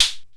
DB - Percussion (14).wav